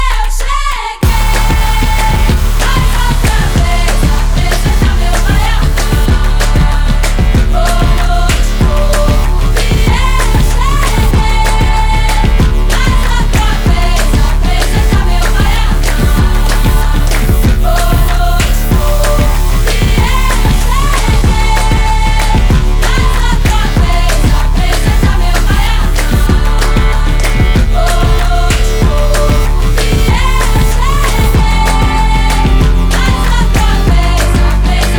Bass Electronic Dance
Жанр: Танцевальные / Электроника